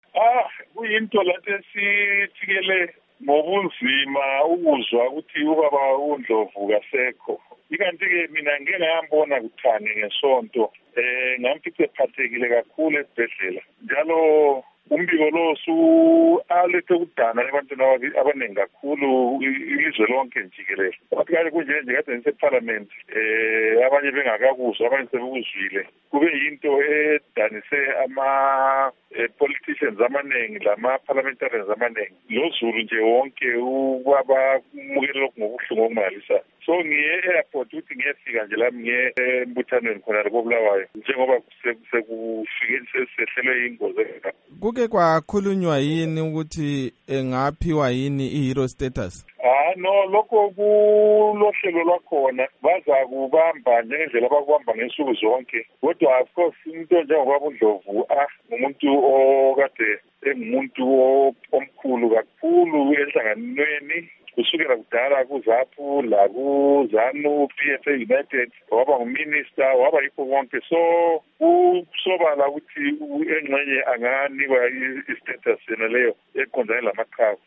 Kusenjalo ibandla leZanu PF lithi khona lizahlala phansi kukhulunyiswane ngodaba lokuthi uzaphiwa na inhlonipho yeqhawe lesizwe kumbe hatshi. Sixoxe lomphathintambo wezokuhlelo ezenotho yelizwe, uMnu. Obert Mpofu ukuze sizwe okunengi ngaloludaba.
Ingxoxo loMnu. Obert Mpofu